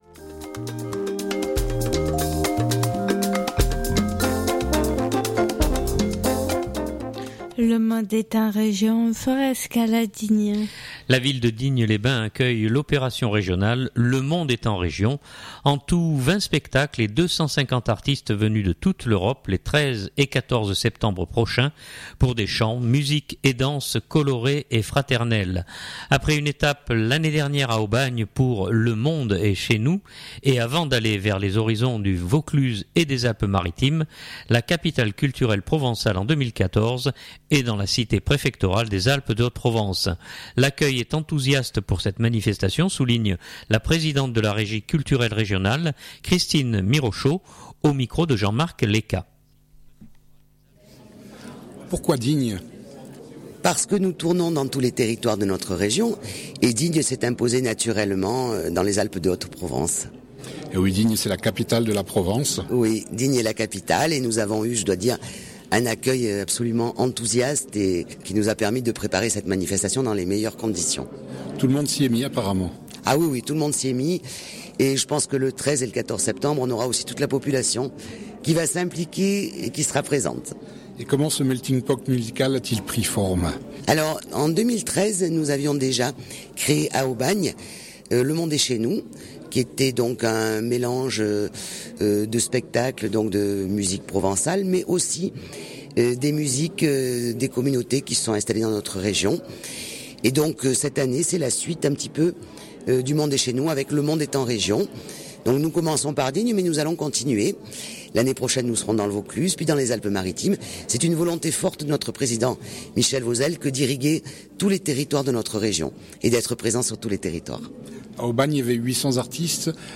Durée du reportage : 3'42"